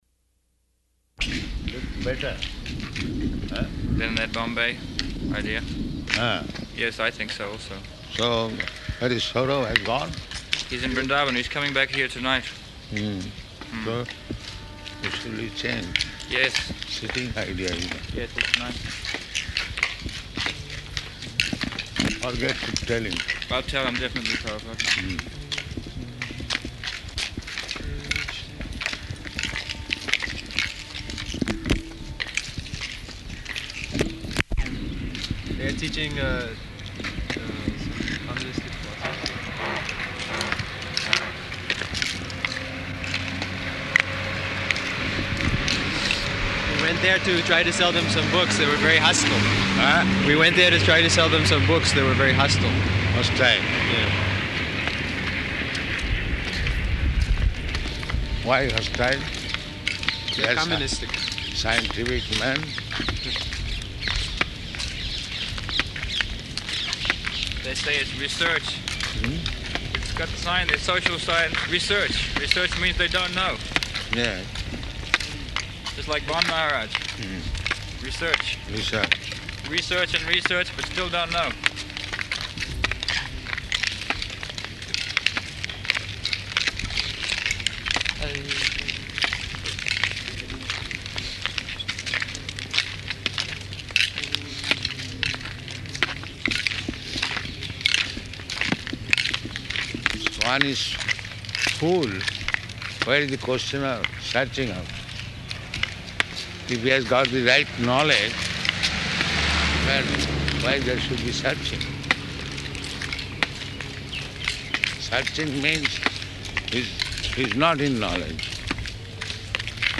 Morning Walk --:-- --:-- Type: Walk Dated: November 26th 1975 Location: Delhi Audio file: 751126MW.DEL.mp3 Prabhupāda: ...looks better, eh?